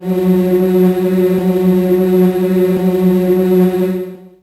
55be-syn09-f#2.wav